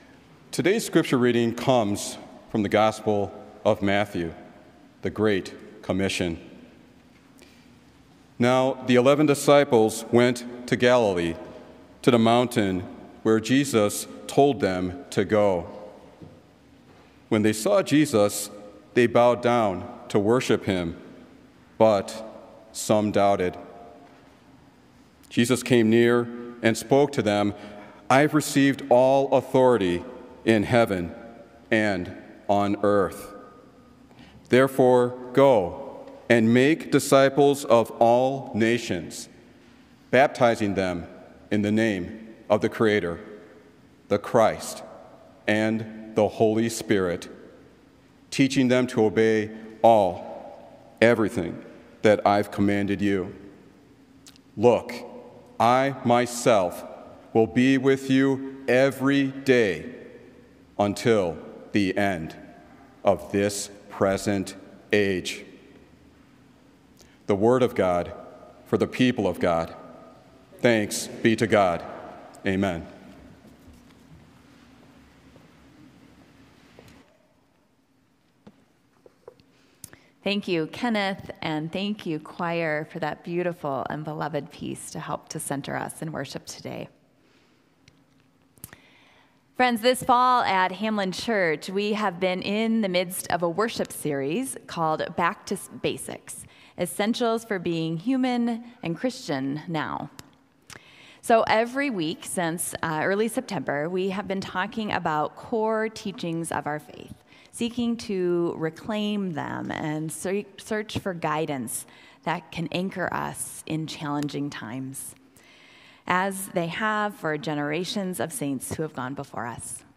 Join us Sunday as we celebrate Hamline University Homecoming and continue our Back to Basics: Essentials for Being Human and Christian Now worship series.